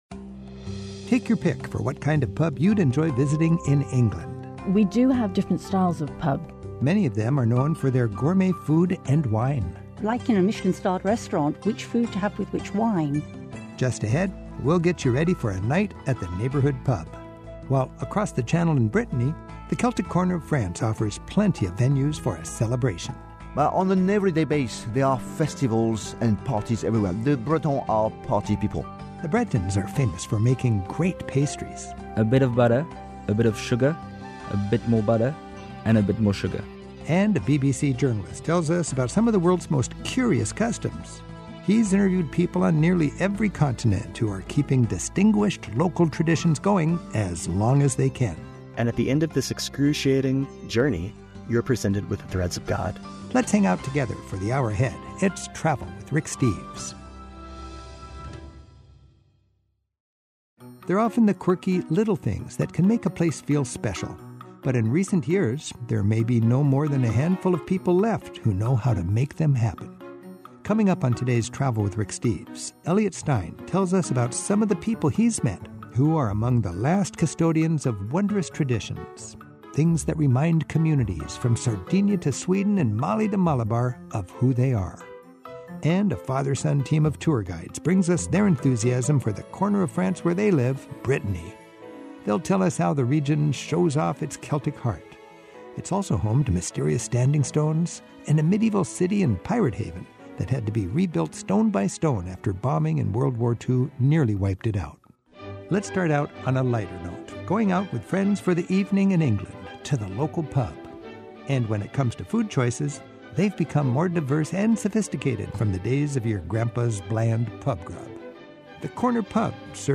My Sentiment & Notes 783 British Pubs; Why I Love Brittany; Custodians of Wonder Podcast: Travel with Rick Steves Published On: Sat Feb 22 2025 Description: Two English tour guides explain what Brits mean by a "rough and ready" pub and how to find some of the UK's best pub grub. Then a father-and-son tour-guiding duo invite us to fall in love with their favorite corner of France.